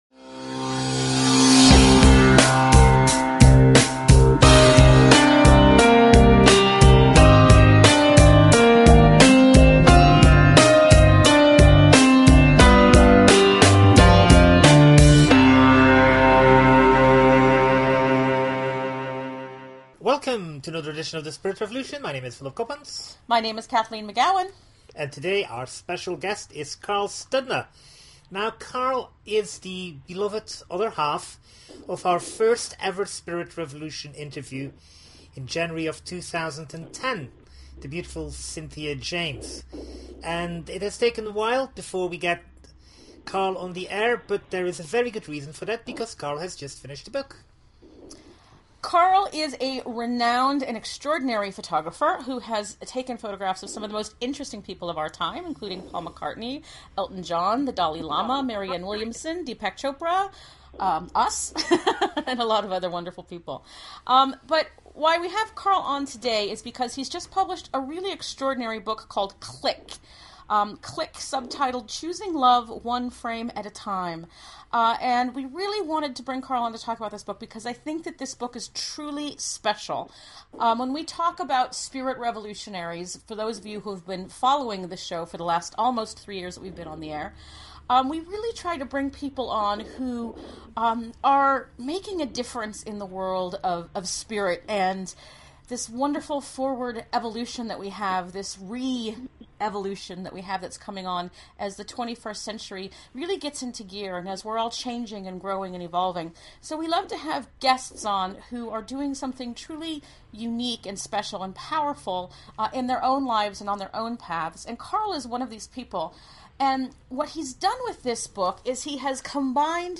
Talk Show Episode, Audio Podcast, The_Spirit_Revolution and Courtesy of BBS Radio on , show guests , about , categorized as
The Spirit Revolution is a weekly one hour radio show, in which Kathleen McGowan and Philip Coppens serve up a riveting cocktail of news, opinion and interviews with leaders in the fields of alternative science, revisionist history and transformational self-help.